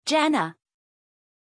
Pronunciation of Jana
pronunciation-jana-zh.mp3